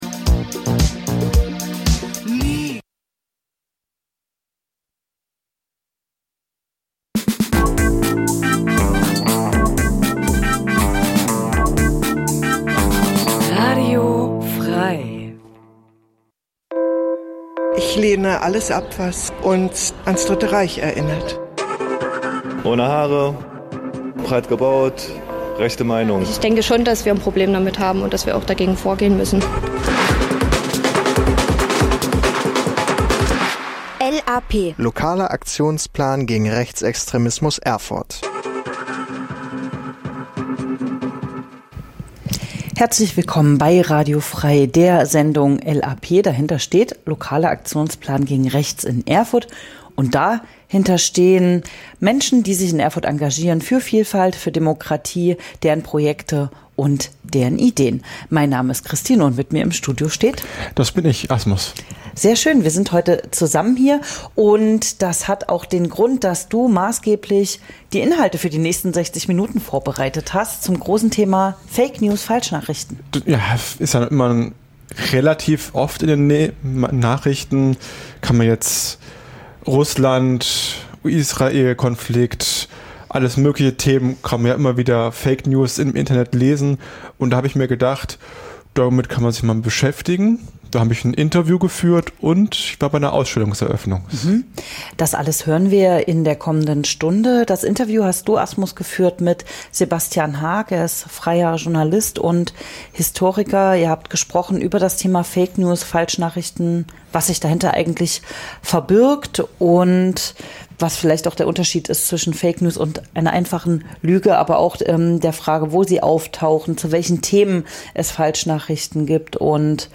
Interview mit dem freien Journalisten